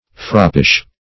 Froppish \Frop"pish\, a.